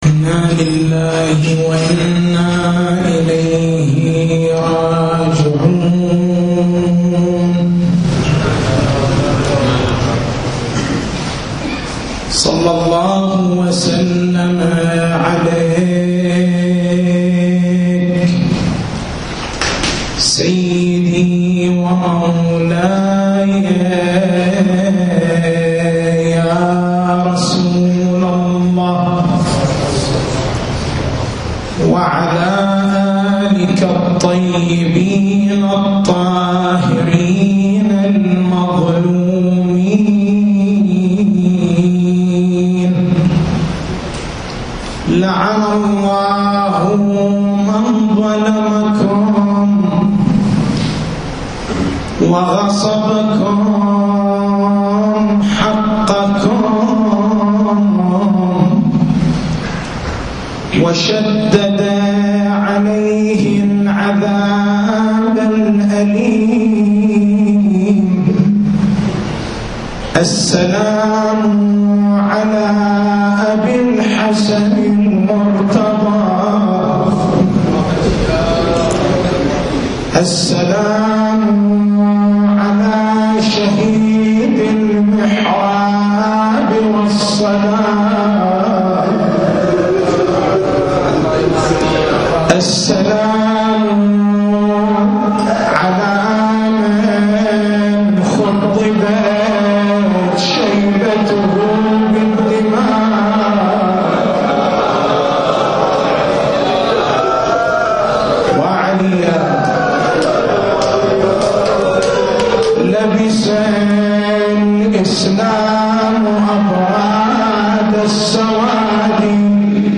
تاريخ المحاضرة: 19/09/1430 نقاط البحث: إذا كان أمير المؤمنين (ع) عالمًا بشهادته، فكيف أقدم على الذهاب إلى مسجد الكوفة، مع أن ذلك إلقاء باليد في التهلكة؟